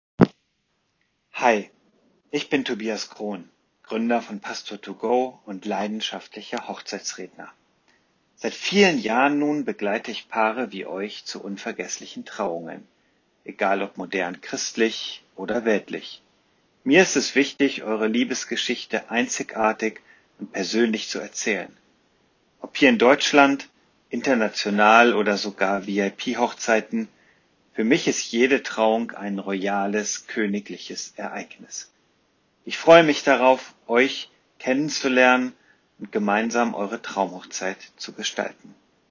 So klingt meine Stimme